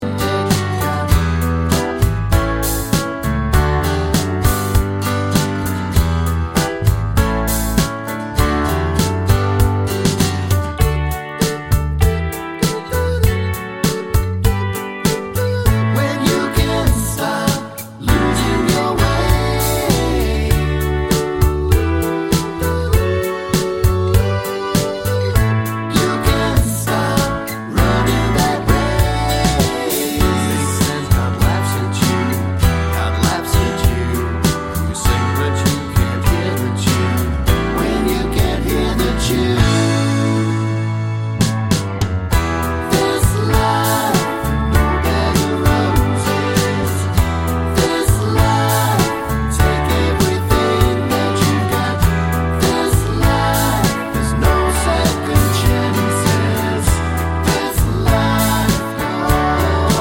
Pop (2020s)